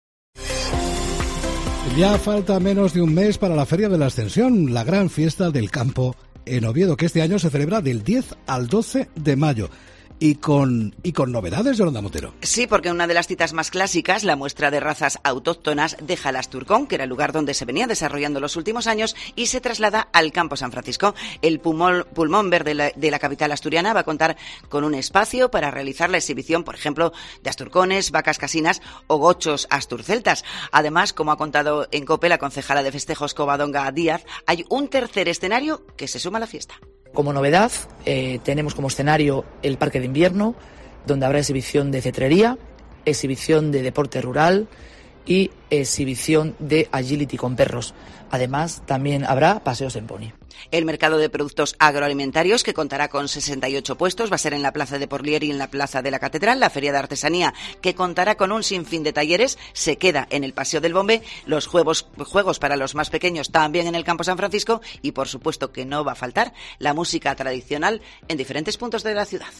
Se trata de El parque de Invierno “Donde disfrutaremos de una exhibición de agility (modalidad competitiva de perros), de deporte rural, de cetrería y paseos en pony para los más pequeños”, ha adelantado Covadonga Díaz, la concejala de Festejos, en COPE.